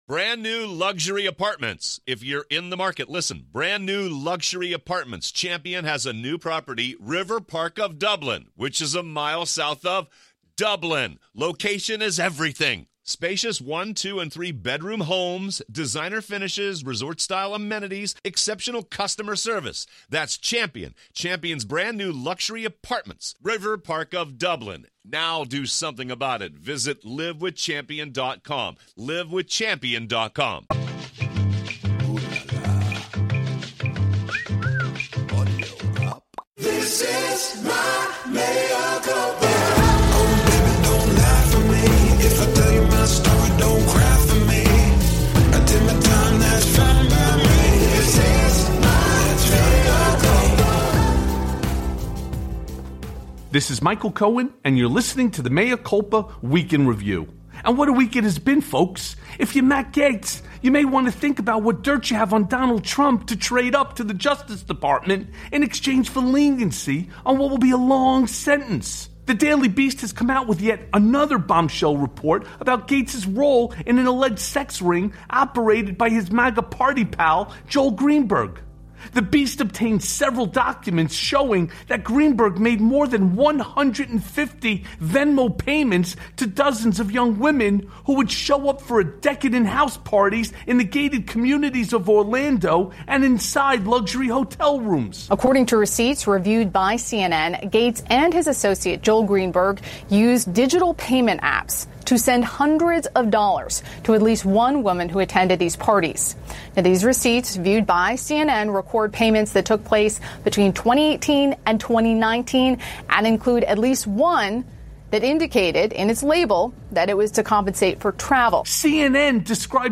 Eyewitness Recounts Matt Gaetz Drug Use at Secret MAGA Sex Party + A Conversation with Lawrence Lessig